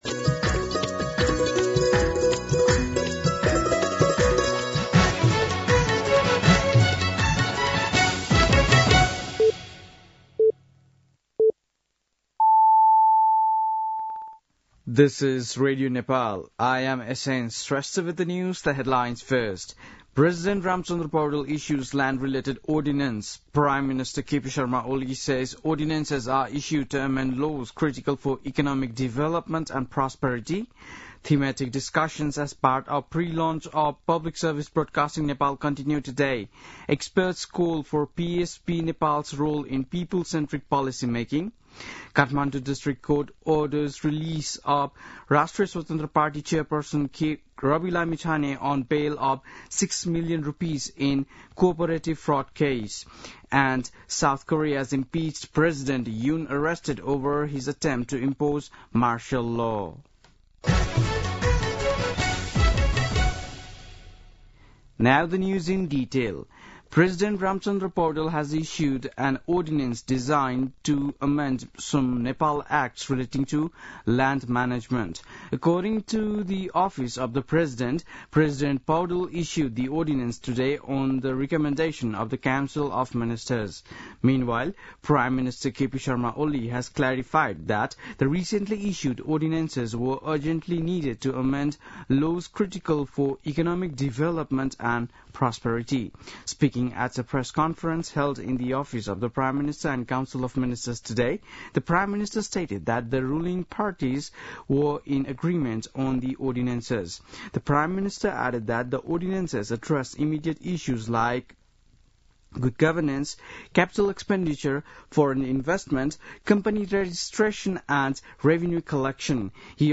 बेलुकी ८ बजेको अङ्ग्रेजी समाचार : ३ माघ , २०८१
8-pm-news-1.mp3